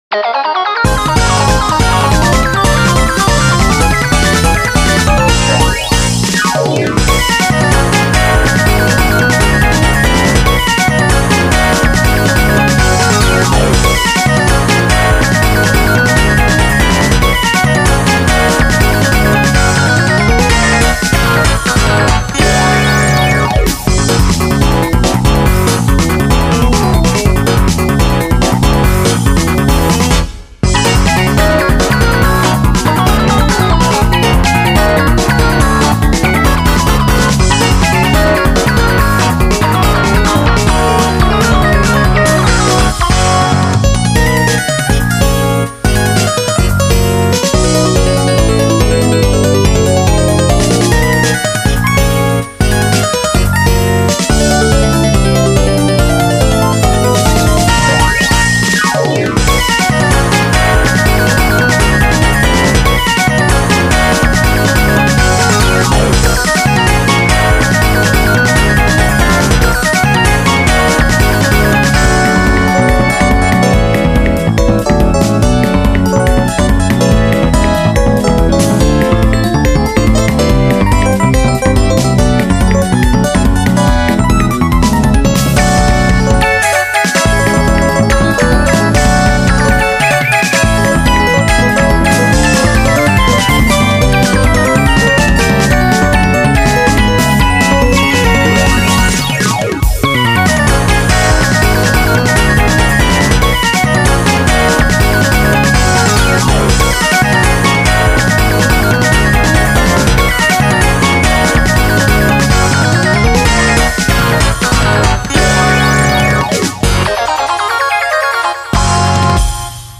BPM142
Audio QualityPerfect (High Quality)
Genre: FUSION